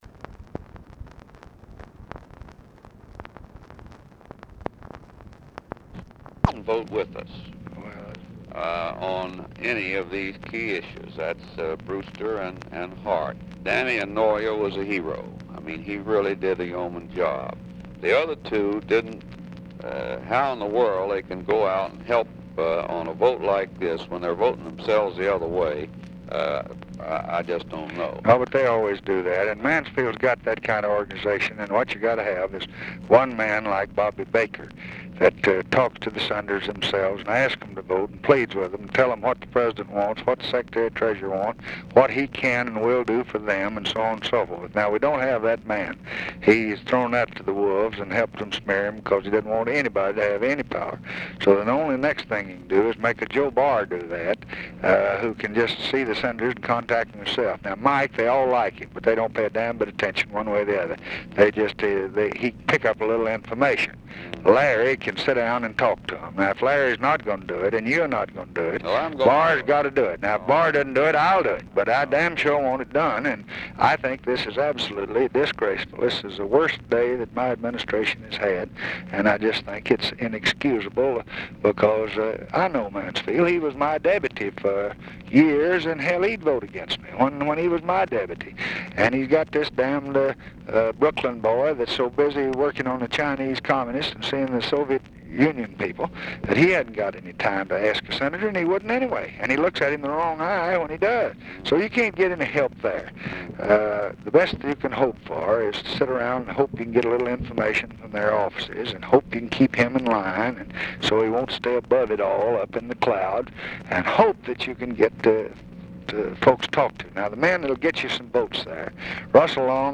Conversation with HENRY FOWLER, March 10, 1966
Secret White House Tapes